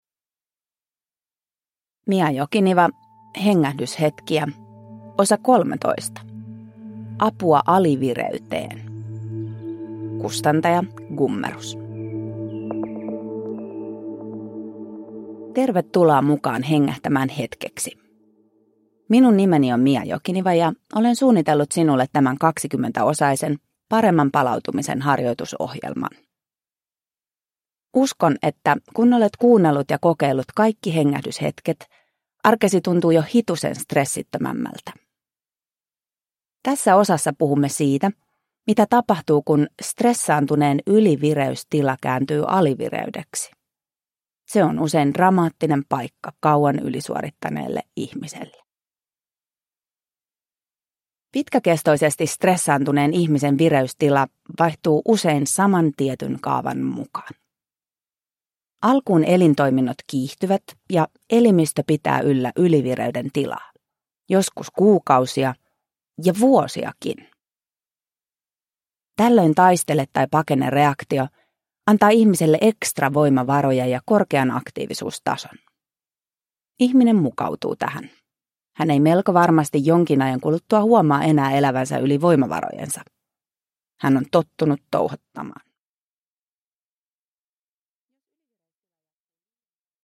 Hengähdyshetkiä (ljudbok